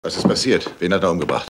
Hörprobe des deutschen Synchronschauspielers